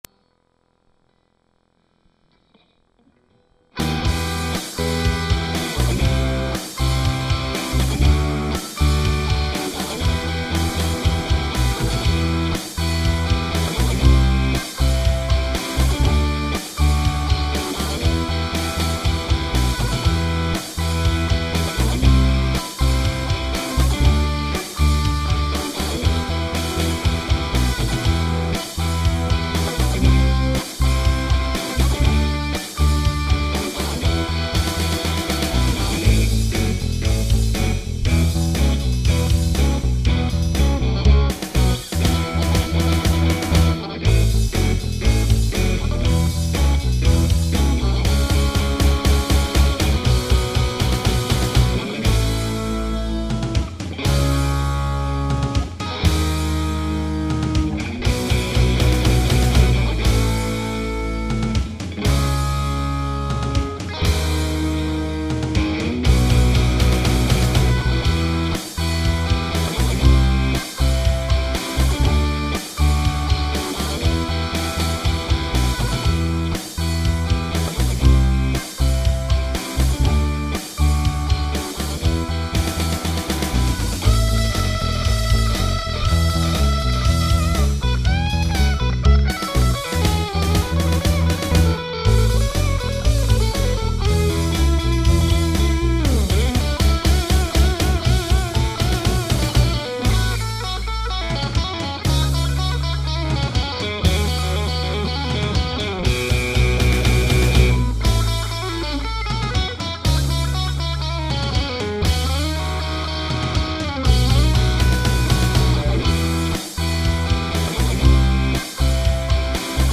c'est avec une Strat une ts9 et Revalver (simu d'ampli)
r.mp3 Fais pas gaffe au mix, c'était ma toute première tentative...